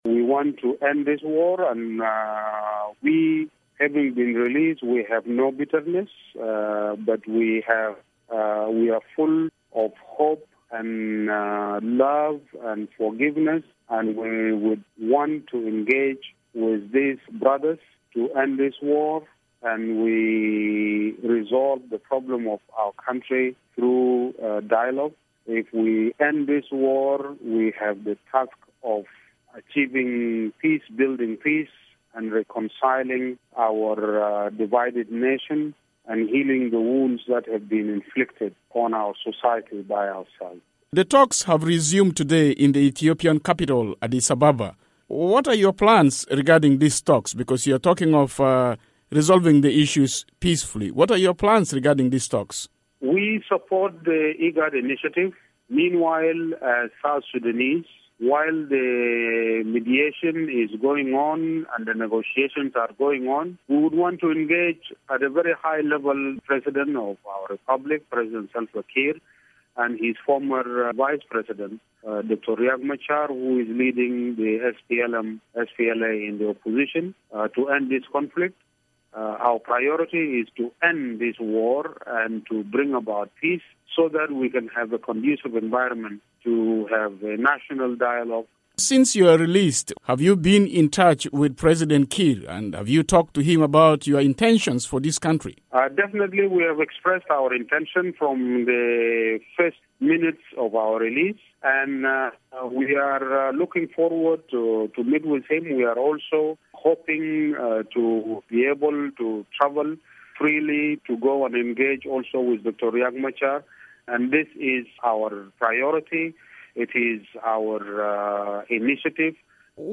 Former SPLM secretary general Pagan Amum says in an interview that, now that he has been released from jail, he will devote himself to restoring peace in South Sudan.